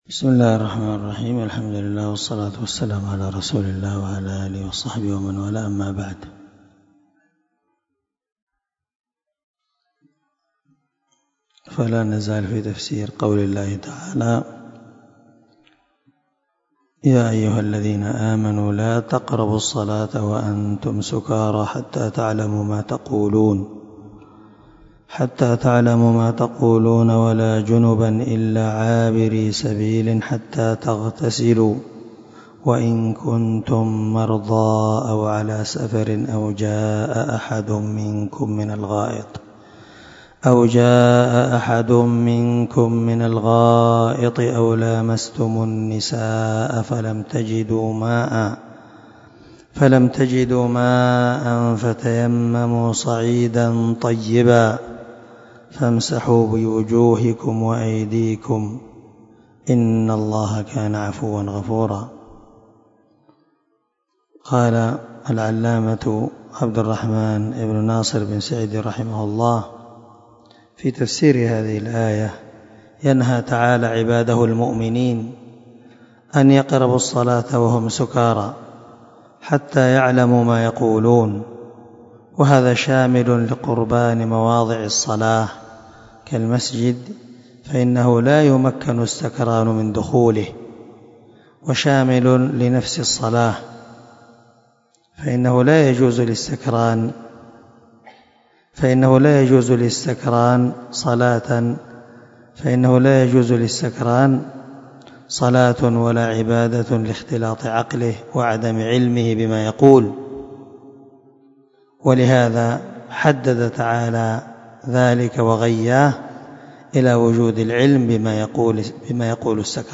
265الدرس 33 تابع تفسير آية ( 43 ) من سورة النساء من تفسير القران الكريم مع قراءة لتفسير السعدي